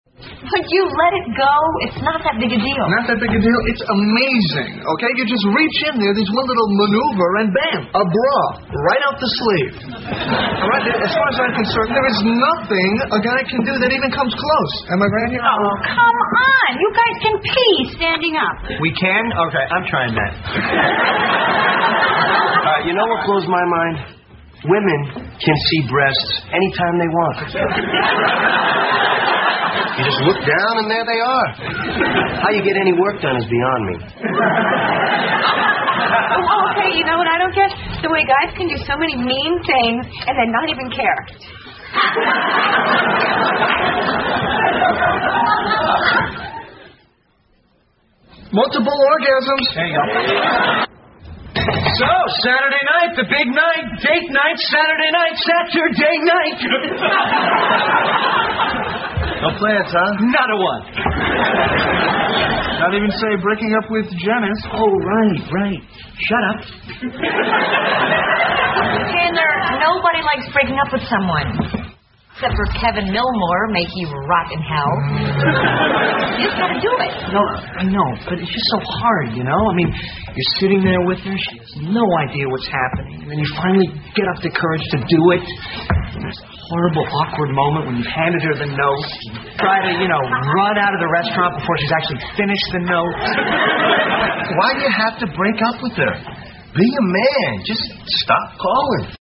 在线英语听力室老友记精校版第1季 第48期:洗衣服(1)的听力文件下载, 《老友记精校版》是美国乃至全世界最受欢迎的情景喜剧，一共拍摄了10季，以其幽默的对白和与现实生活的贴近吸引了无数的观众，精校版栏目搭配高音质音频与同步双语字幕，是练习提升英语听力水平，积累英语知识的好帮手。